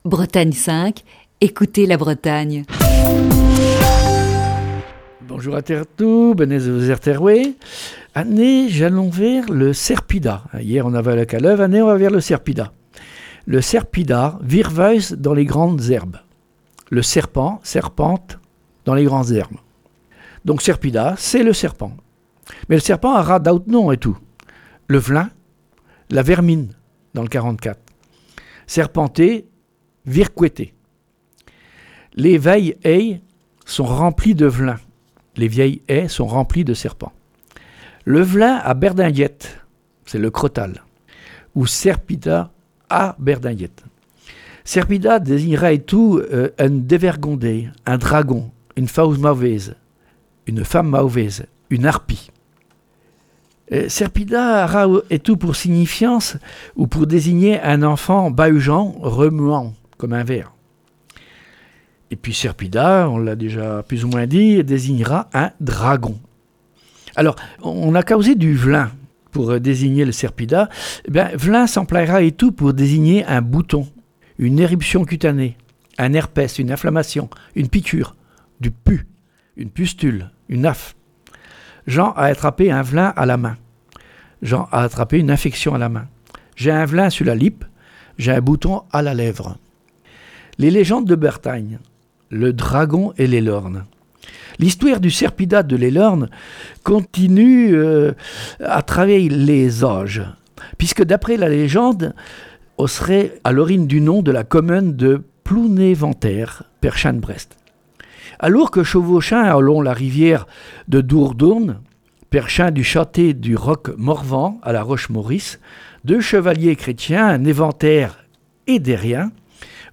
Chronique du 13 janvier 2021.